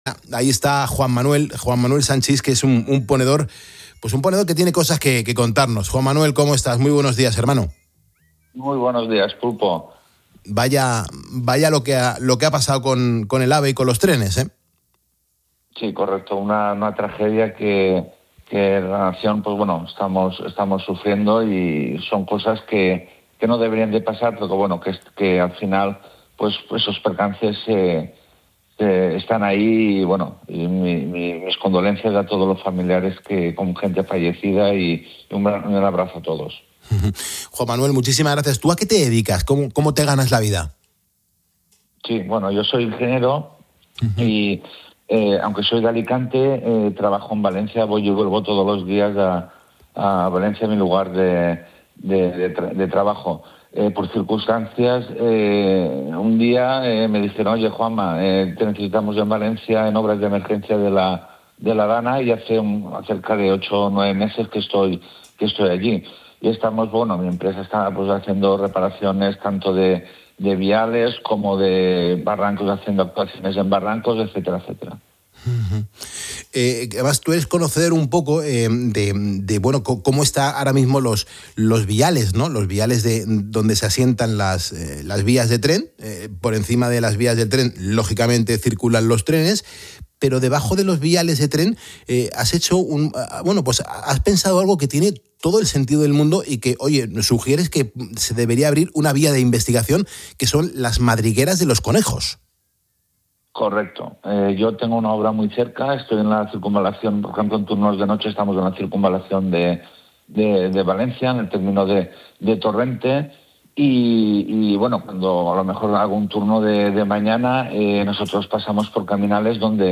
Un oyente del programa 'Poniendo las Calles' y experto en infraestructuras sugiere investigar la acción de los conejos como posible causa de la tragedia ferroviaria